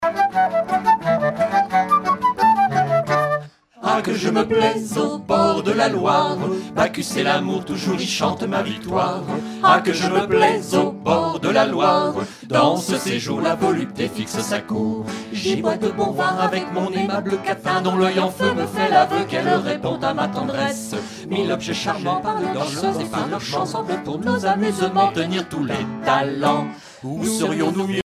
danse : mazurka
Concert donné en 2004
Pièce musicale inédite